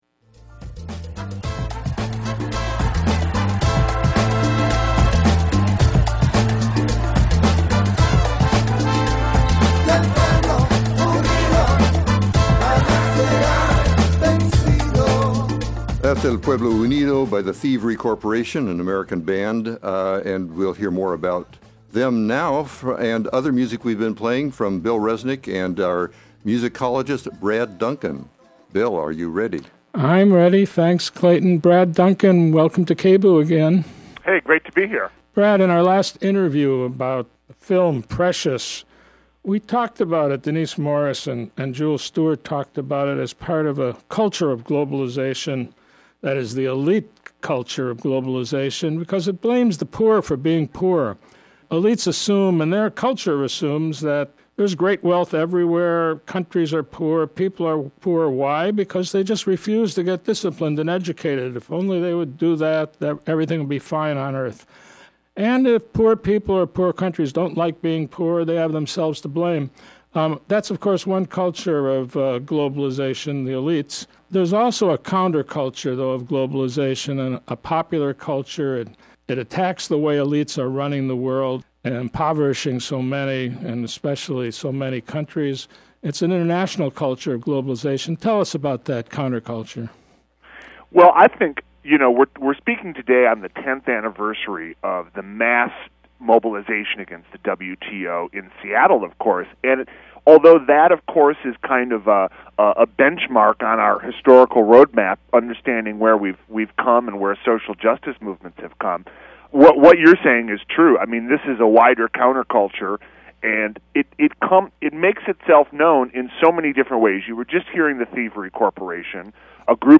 There is always music inspired by, and that  inspires,  political resistance, and these times are no exception.  Radical musicologist